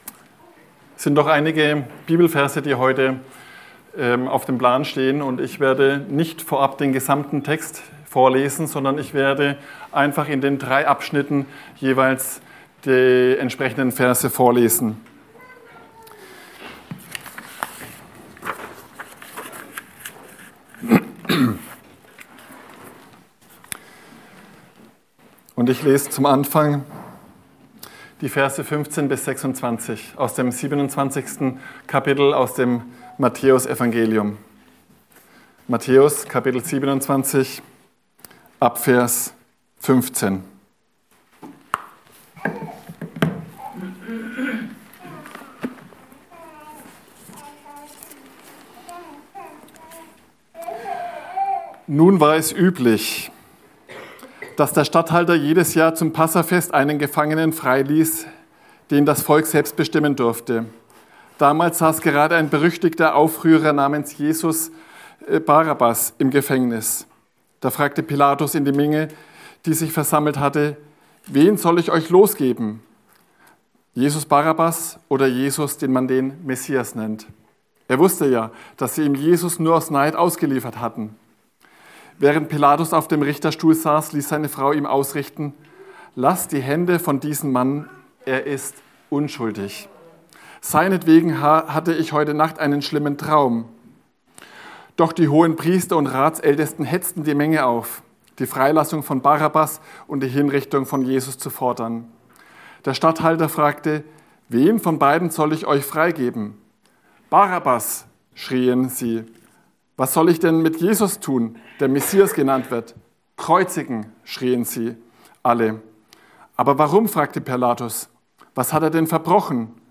Karfreitagpredigt